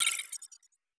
HiTech Click 8.wav